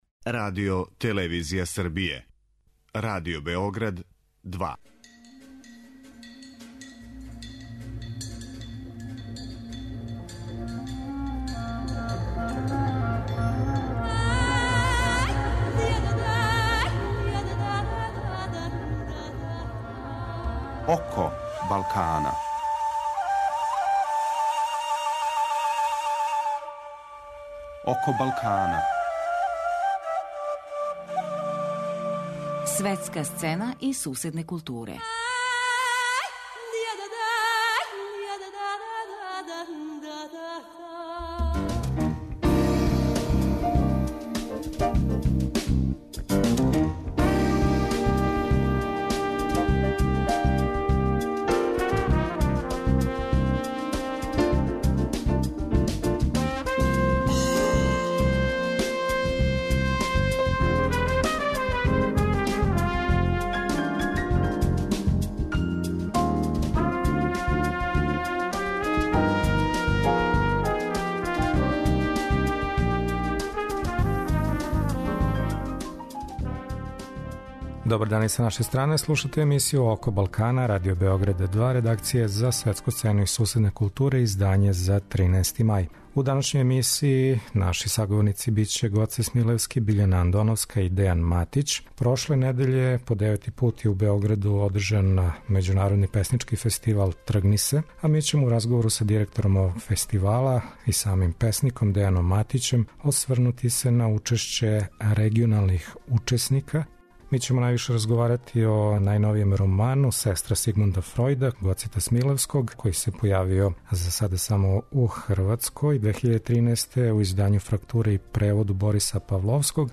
Гост емисије је најпревођенији македонски писац средње генерације Гоце Смилевски. Представићемо његов најновији роман Сестра Сигмунда Фројда (Фрактура, 2013).